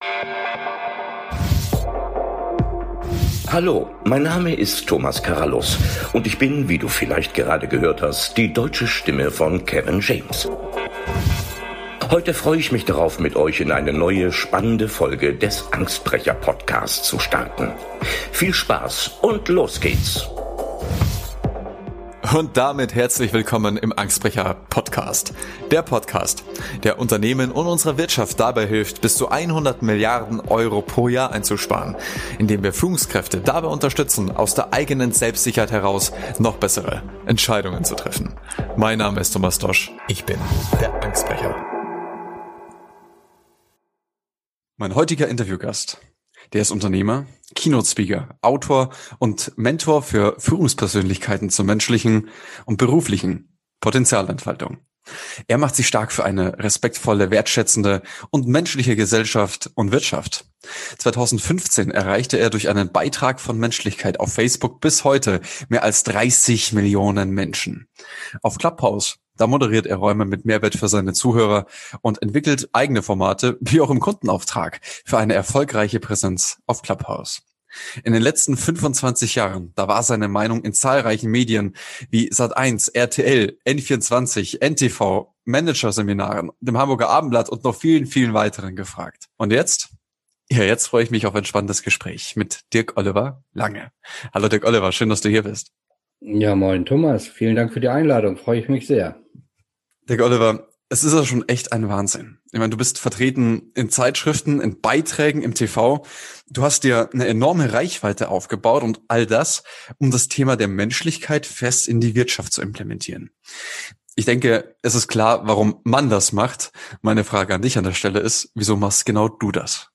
Mein heutiger Interview- Gast ist Unternehmer, Keynote Speaker, Autor und Mentor für Führungspersönlichkeiten zur menschlichen und beruflichen Potenzialentfaltung.